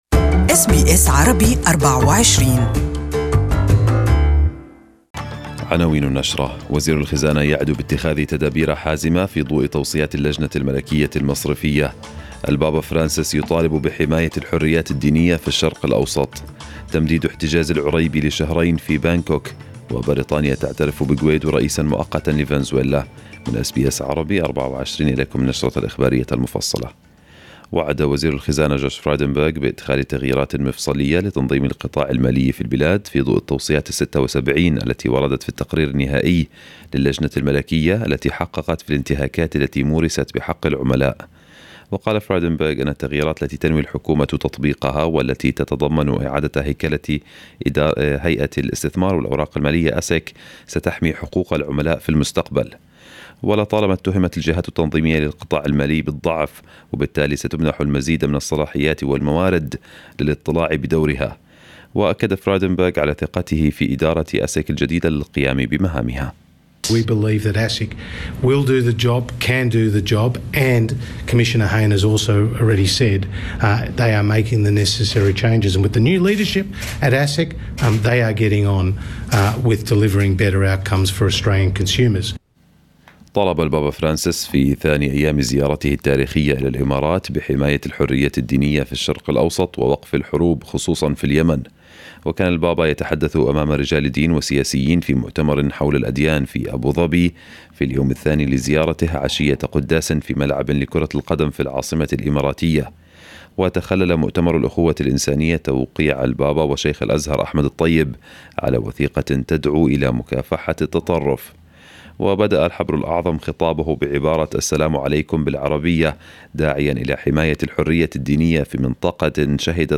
News bulletin in Arabic for this morning